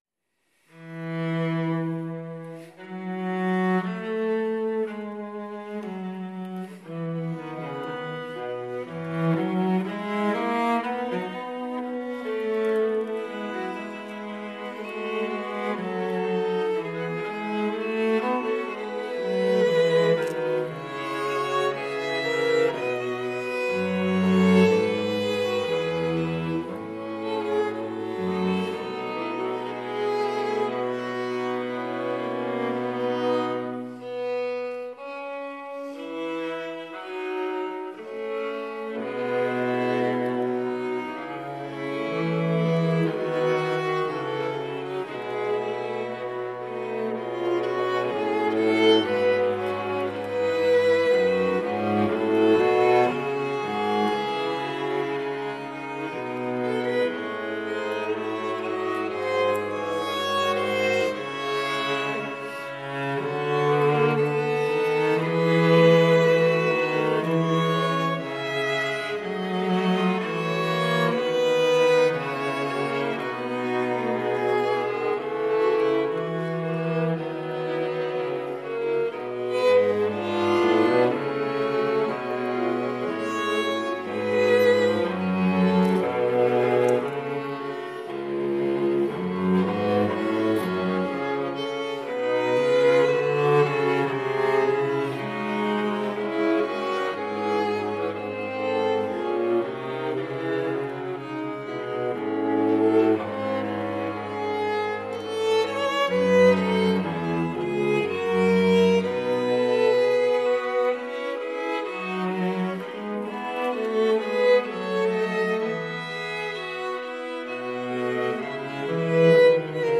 Live at Robinson College Chapel